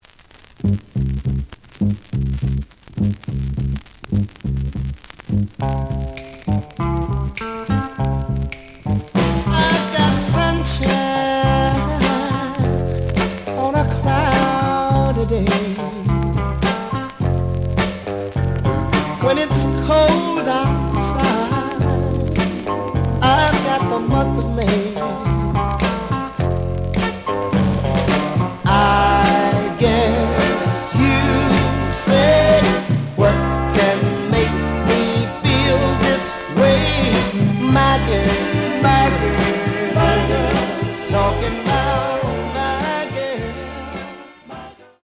The audio sample is recorded from the actual item.
●Genre: Soul, 60's Soul
Slight affect sound.)